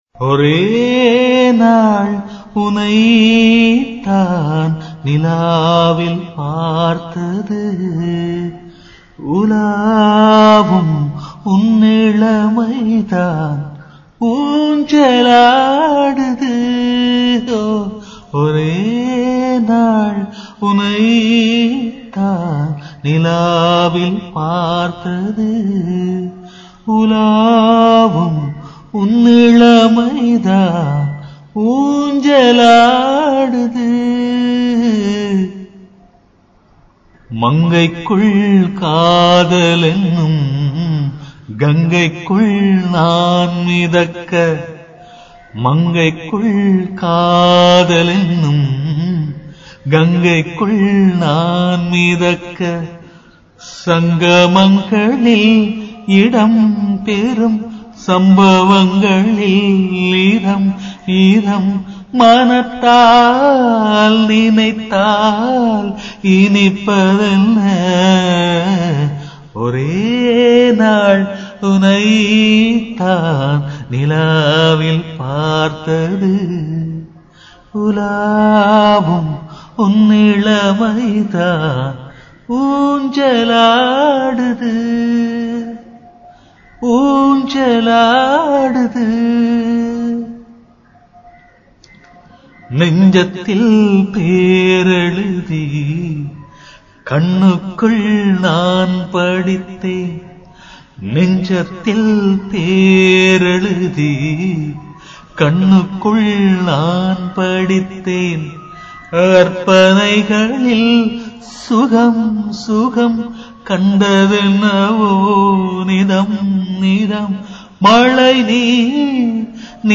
நான் சும்மா பாடிப் பயின்றது